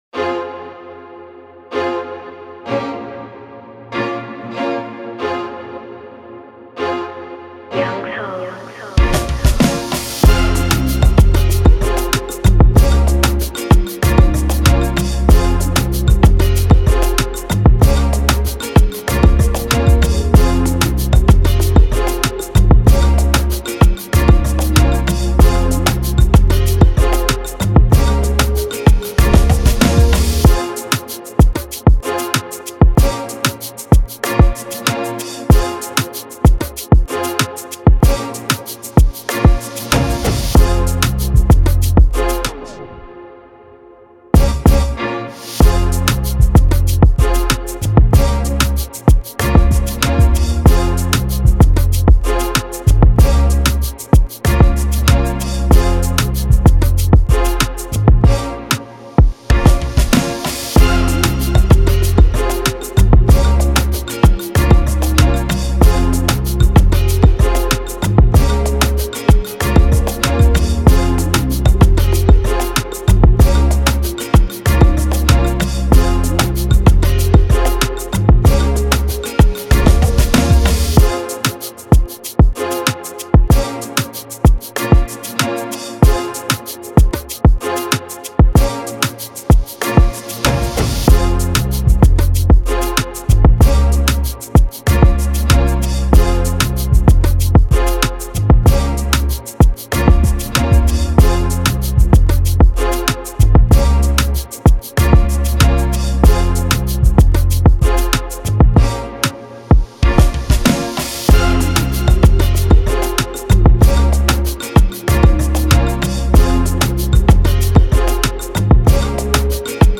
official instrumental
2025 in Dancehall/Afrobeats Instrumentals